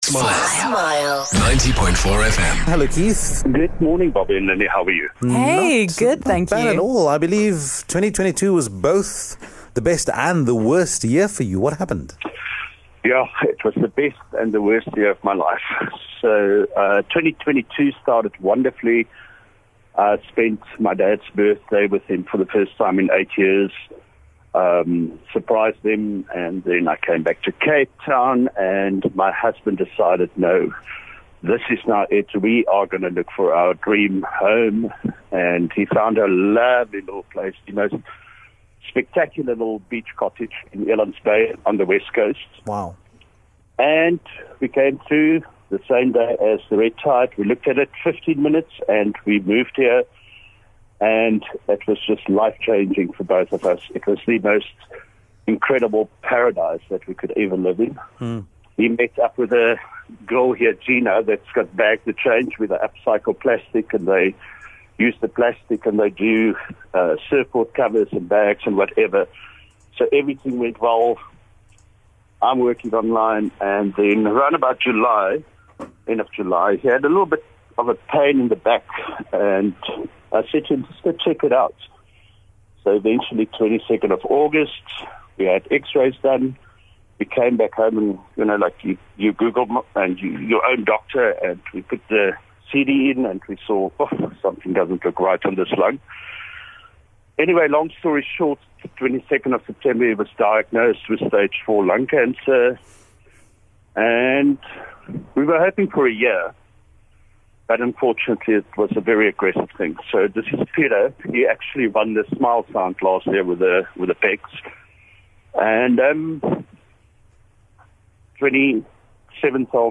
There are moments on radio that knock us over and leave us in tears.